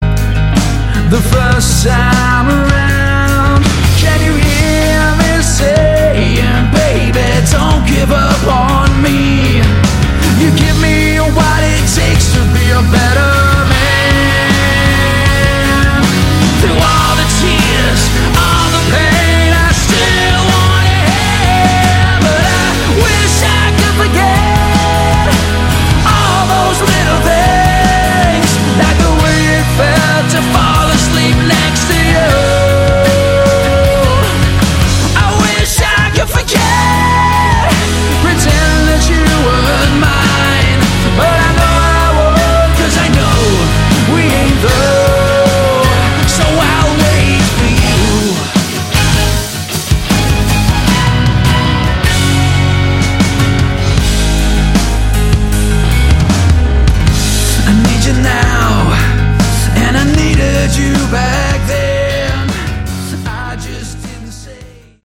Category: Hard Rock
vocals
lead guitar
rhythmn guitar
bass
drums